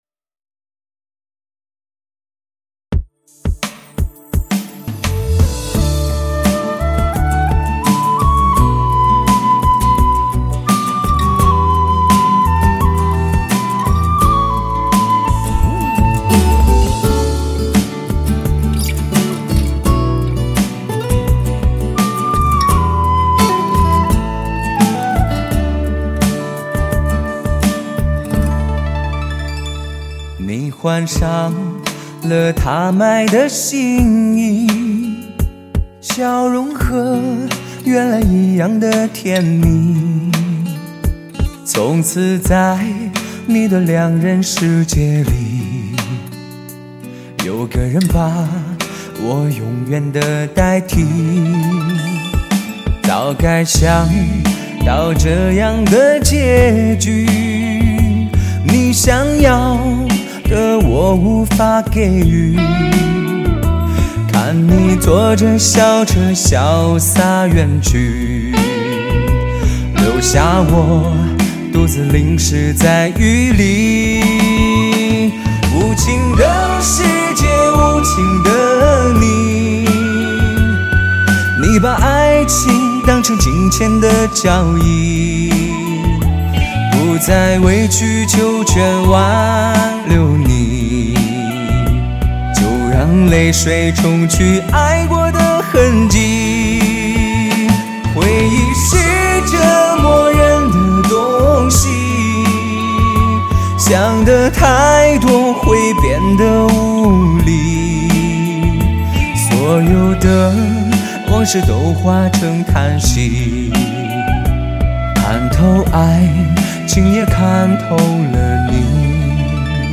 男声HI-FI碟